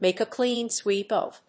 アクセントmàke a cléan swéep of…